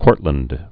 (kôrtlənd)